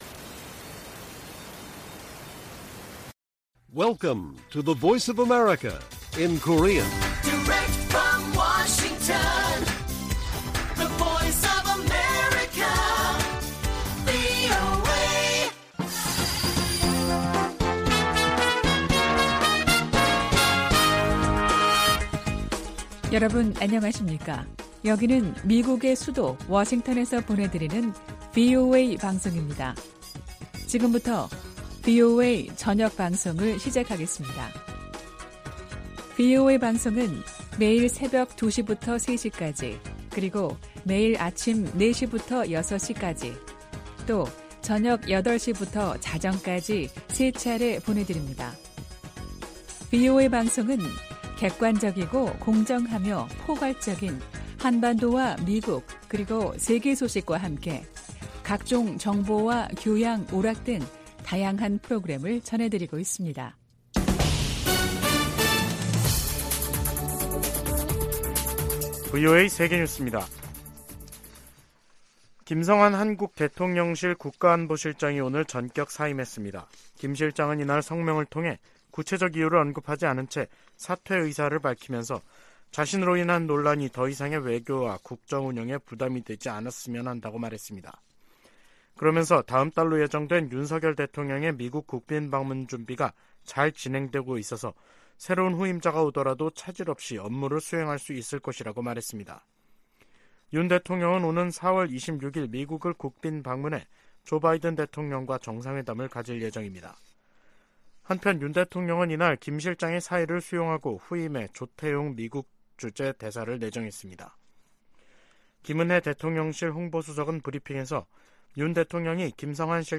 VOA 한국어 간판 뉴스 프로그램 '뉴스 투데이', 2023년 3월 29일 1부 방송입니다. 백악관은 북한의 전술핵탄두 공개에 국가 안보와 동맹의 보호를 위한 준비태세의 중요성을 강조했습니다. 국무부는 북한의 '핵 공중폭발 시험' 주장에 불안정을 야기하는 도발행위라고 비난했습니다. 전문가들은 북한이 핵탄두 소형화에 진전을 이룬 것으로 평가하면서 위력 확인을 위한 추가 실험 가능성이 있다고 내다봤습니다.